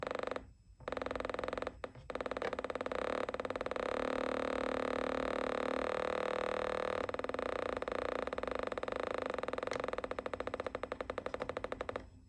keyboard.mp3